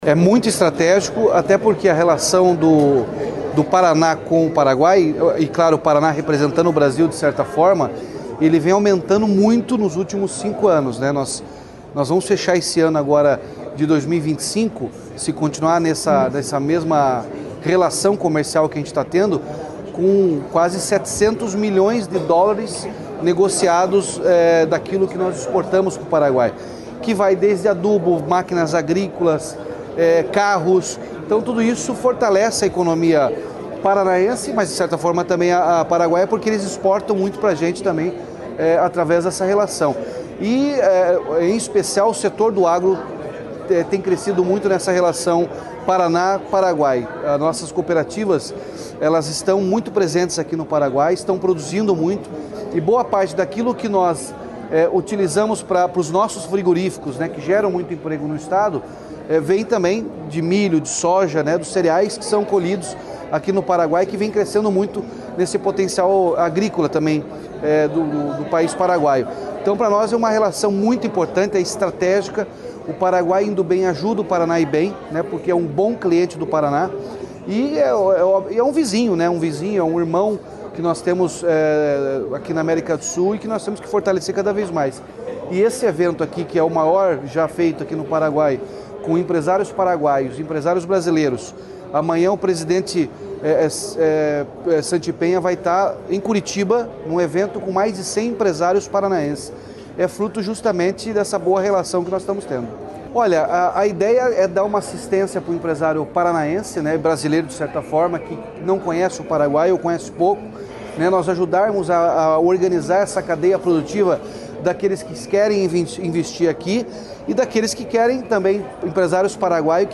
Sonora do governador Ratinho Junior sobre a participação na Expo Paraguai Brasil